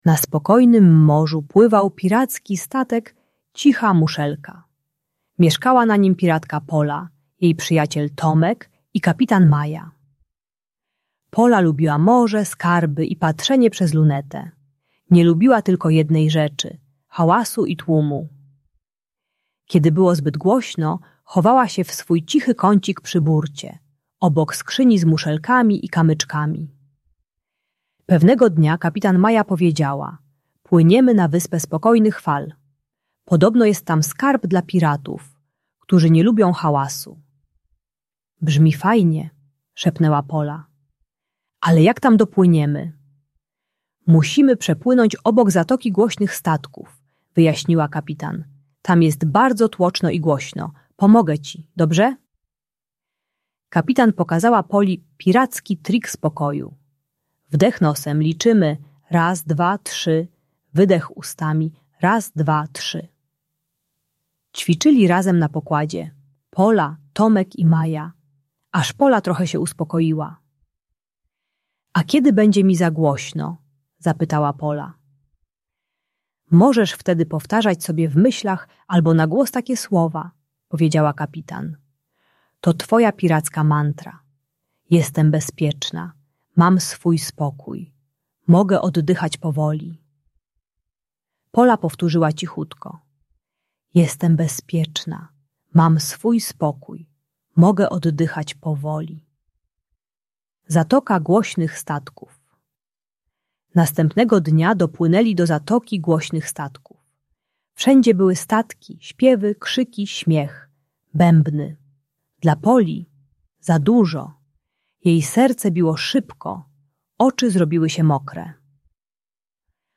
Audiobajka o lęku i wycofaniu społecznym pomaga wrażliwym dzieciom, które czują się przytłoczone w głośnym otoczeniu. Uczy techniki oddychania do trzech, mantry uspokajającej oraz akceptacji potrzeby wycofania się i szukania ciszy.